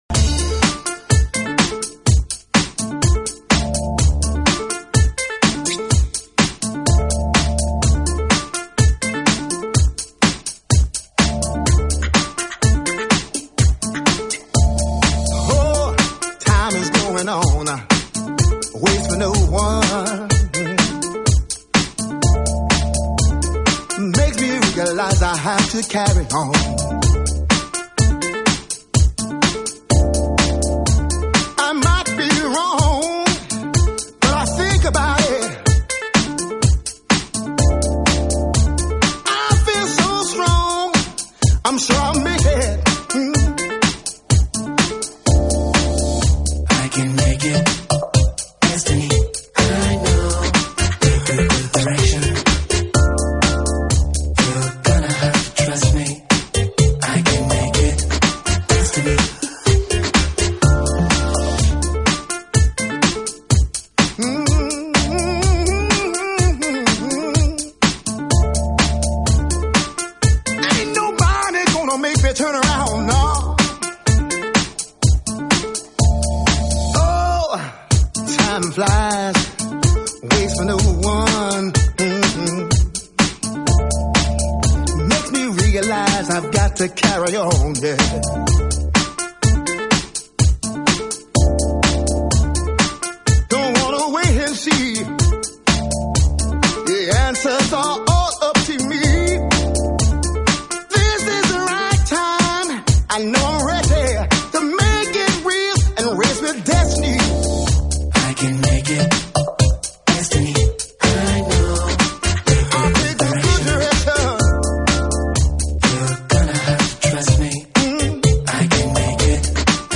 deep cool